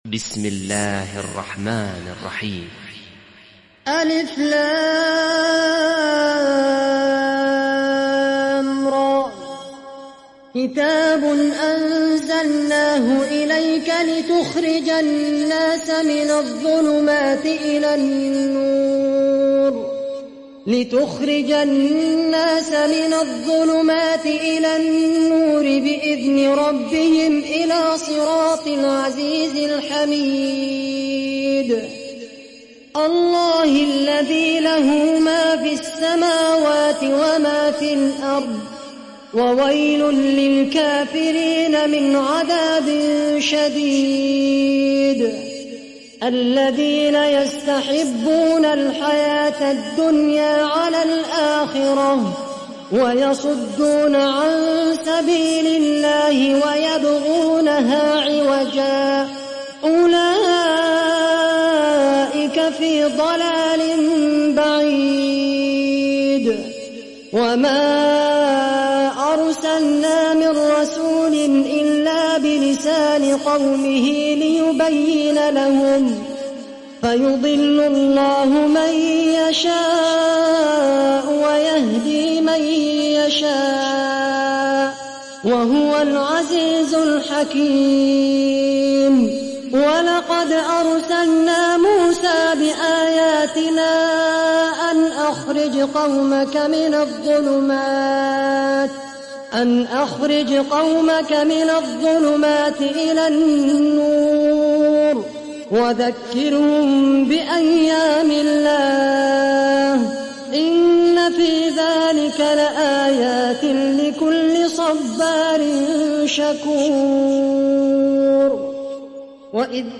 সূরা ইব্রাহীম ডাউনলোড mp3 Khaled Al Qahtani উপন্যাস Hafs থেকে Asim, ডাউনলোড করুন এবং কুরআন শুনুন mp3 সম্পূর্ণ সরাসরি লিঙ্ক